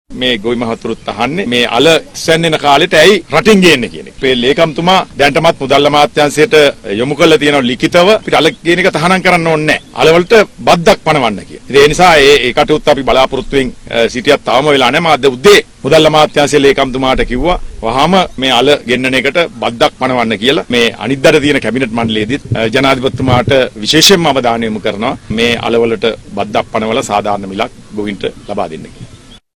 මේ සමබන්ධයෙන්   අමාත්‍ය මහින්ද අමරවීර මහතා ඊයේ දිනයේ මාධ්‍ය වෙත අදහස් පල කළා .